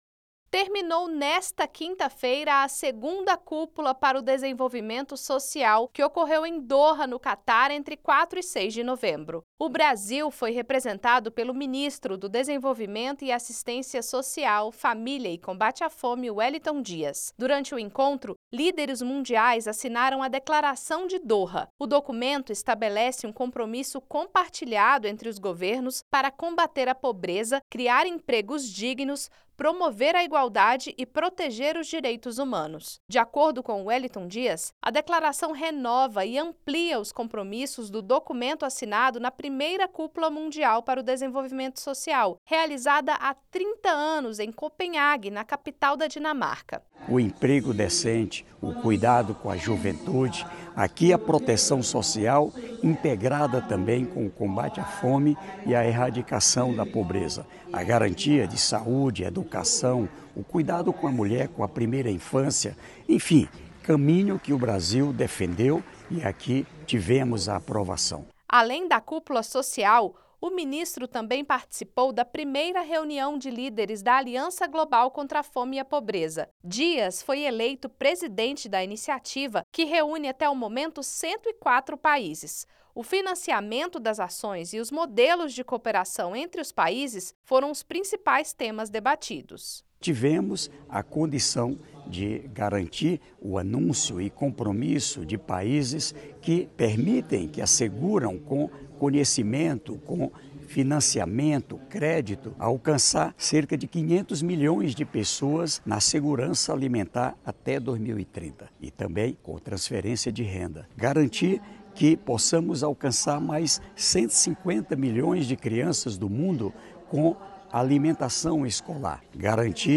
Boletim do MDS